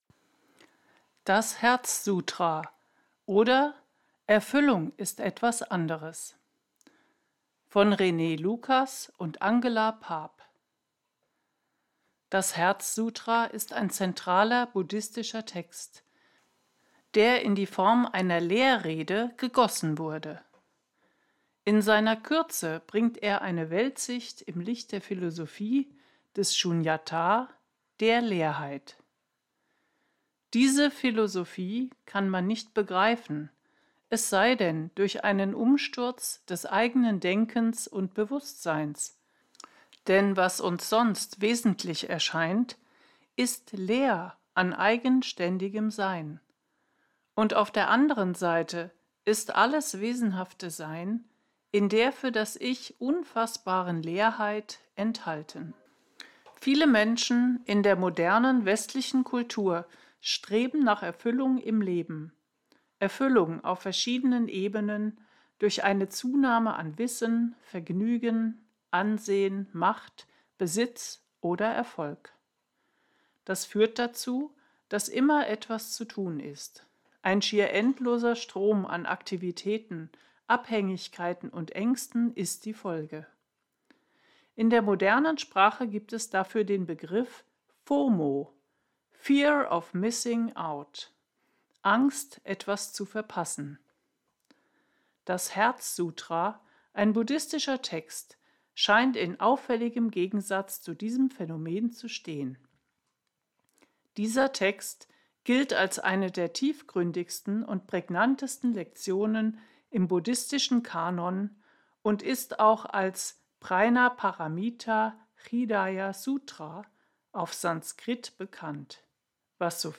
LOGON-Artikel gelesen